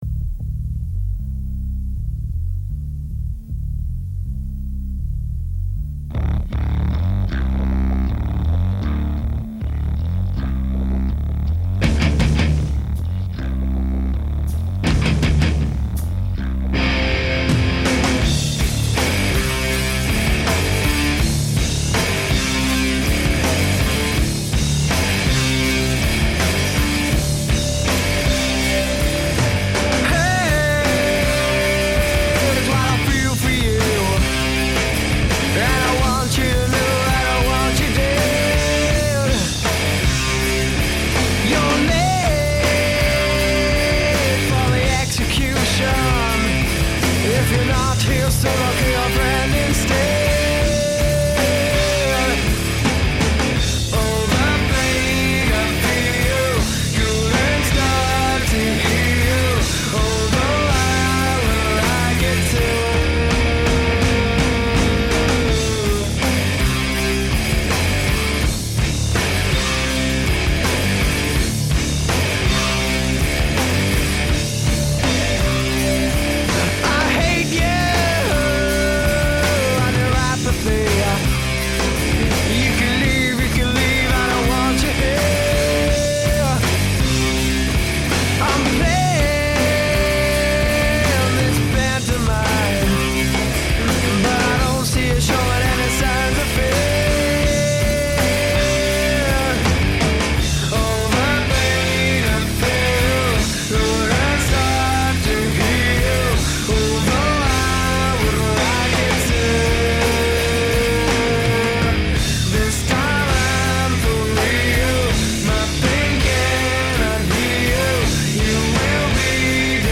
Grunge Rock